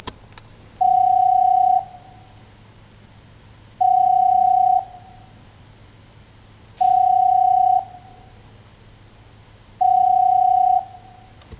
【NAKAYO（ナカヨ）ST101A 着信音サンプル】
■着信音　F